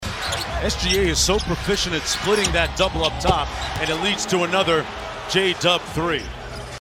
Thunder PBP 11-26.mp3